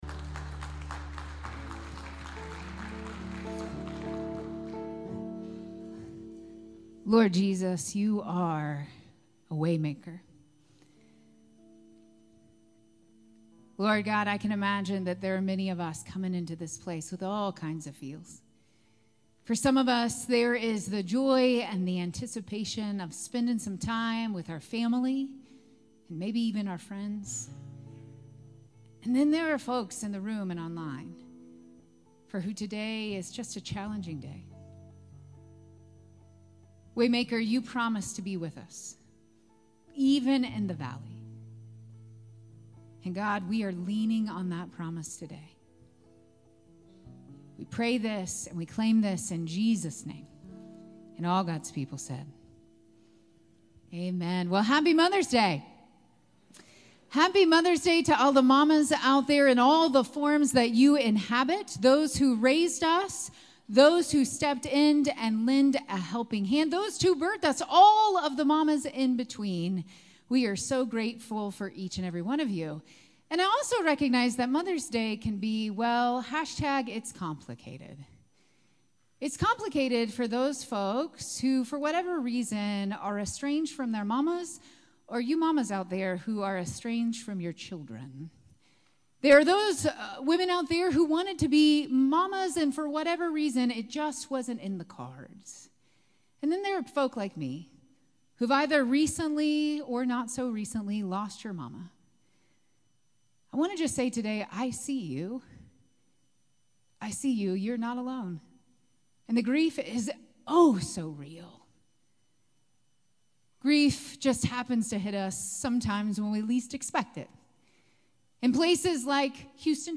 May 12, 2024 Sermon